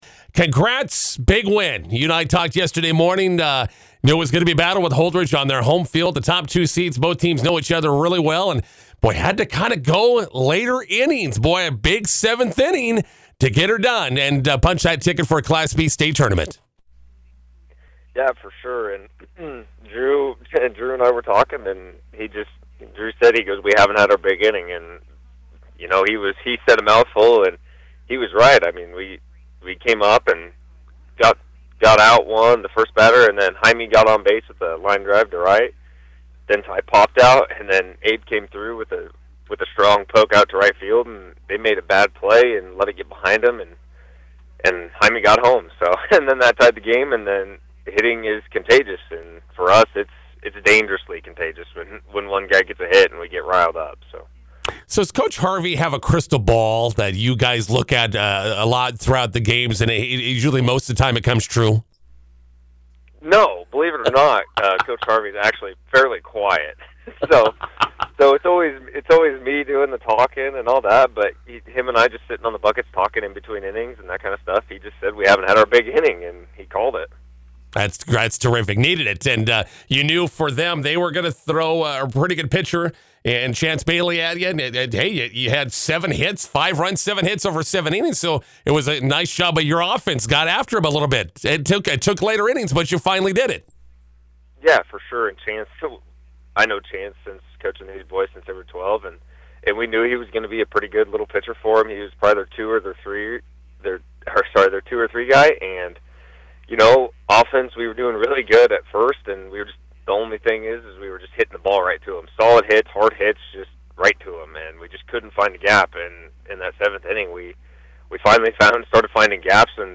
INTERVIEW: First Central Bank Juniors finish the Class B6 area tournament with a championship win over Holdrege, on to Gering for Class B state tourney.